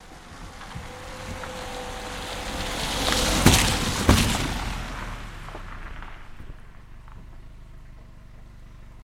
Car On Wood Bridge, Splashes At Tail